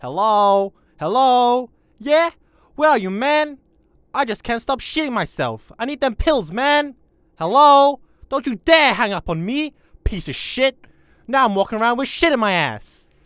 Voice Lines / Street barklines
Update Voice Overs for Amplification & Normalisation
hello HELLO where are you man i cant stop shitting myself.wav